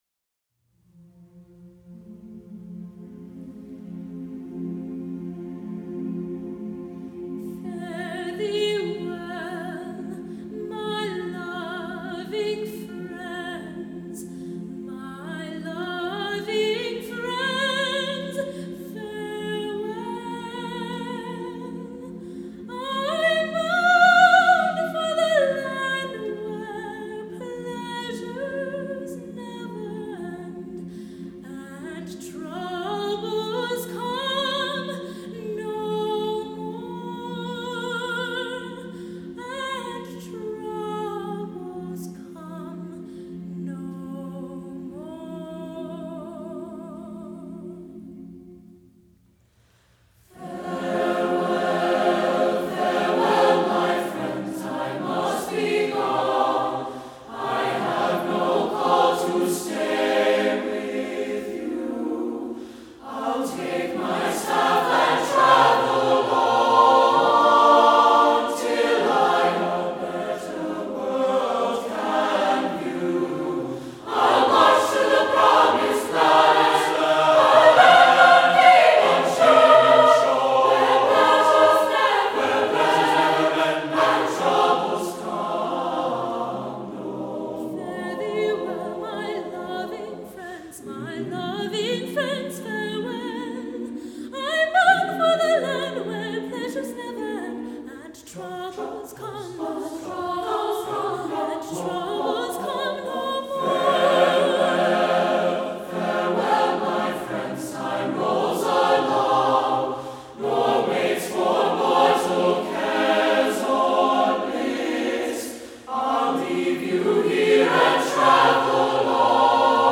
for SATB Chorus and Treble Voices (2002)
Chordal structures are sparse. The meter remains constant.